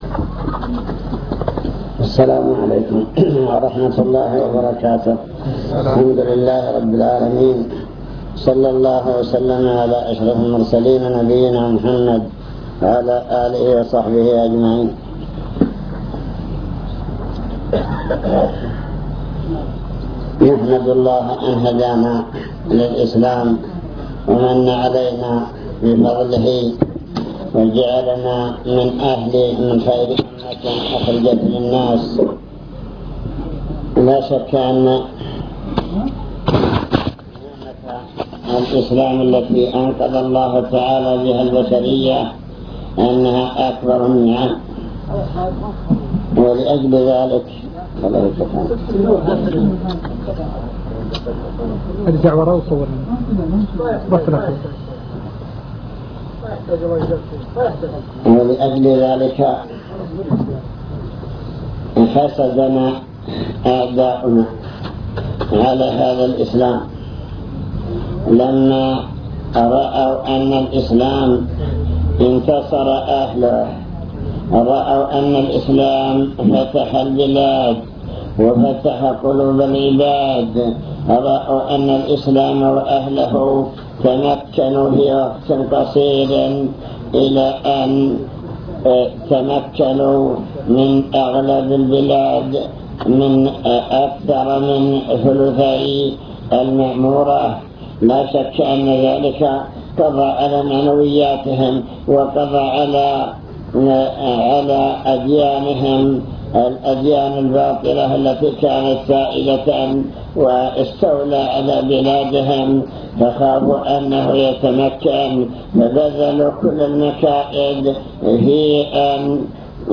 المكتبة الصوتية  تسجيلات - محاضرات ودروس  محاضرات في محافظة الباحة مكائد أعداء الإسلام